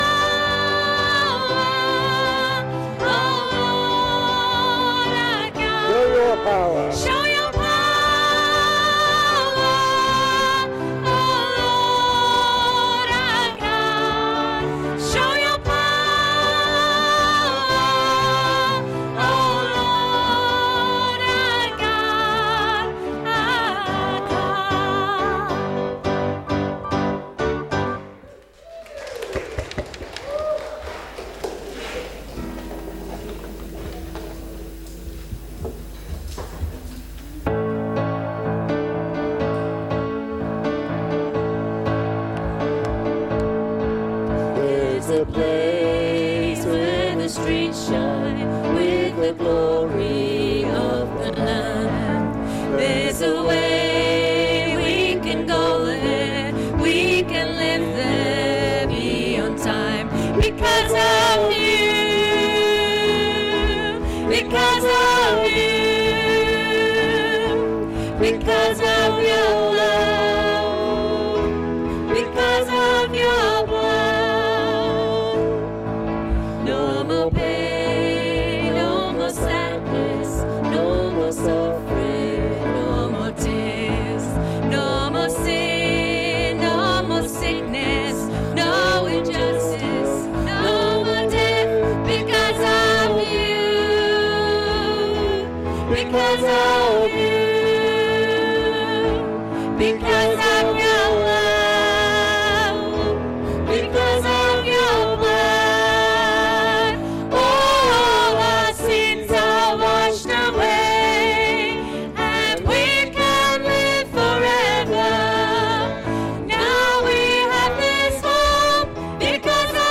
Join us for this month’s praise and worship evening
Service Audio